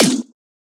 smallFall.ogg